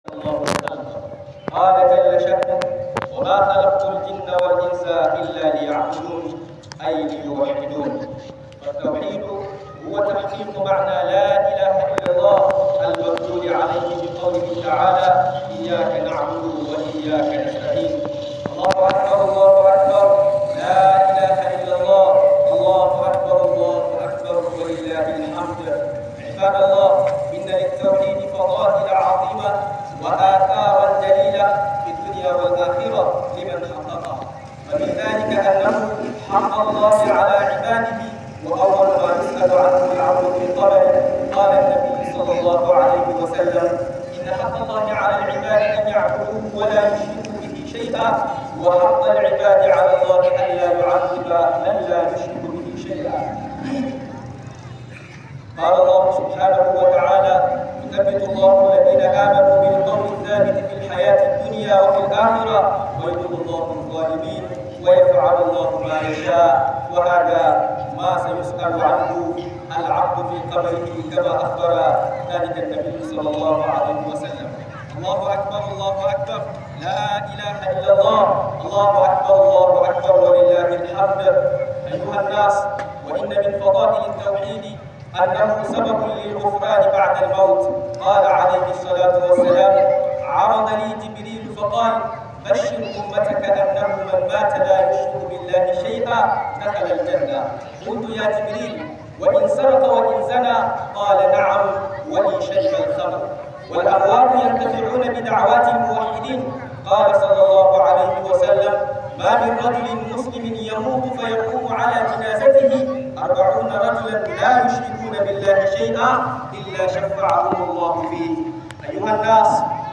Hudubar idi (Tawhidi da Falalar sa) - MUHADARA
Hudubar idi (Tawhidi da Falalar sa)